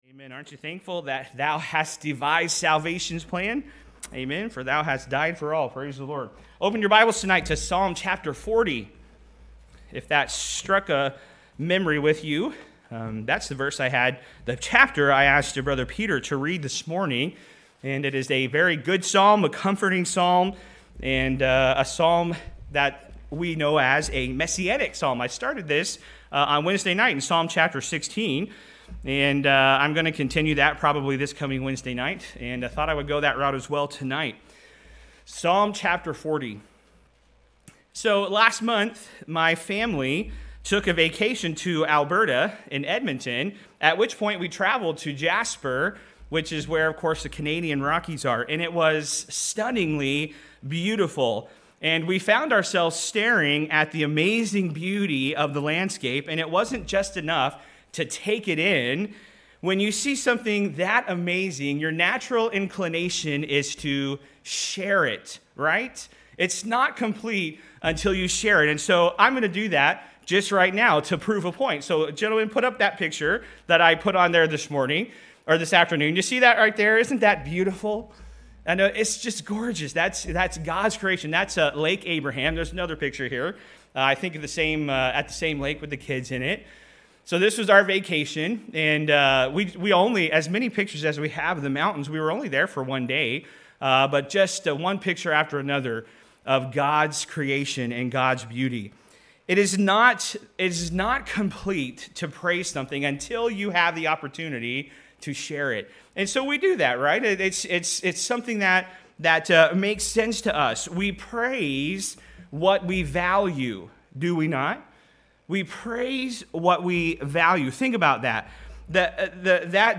Sermons | New Testament Baptist Church